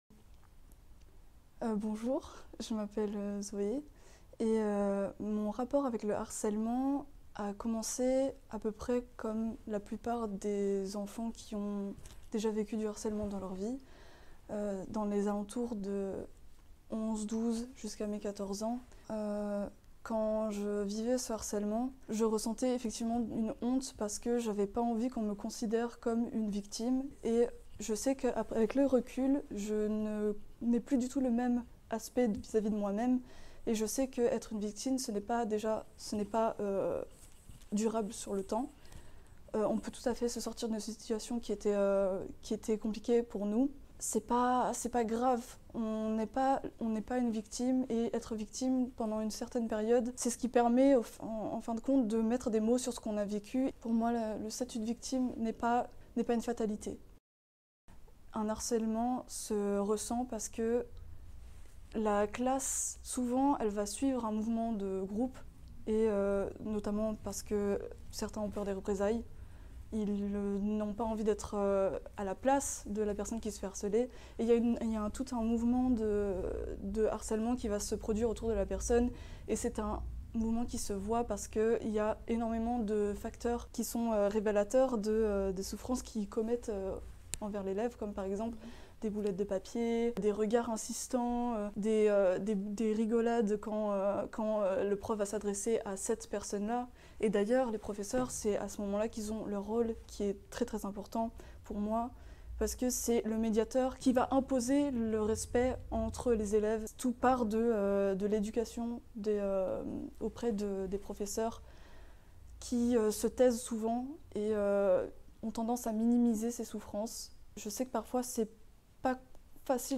Vous voyagerez entre histoires réelles et interviews avec des spécialistes.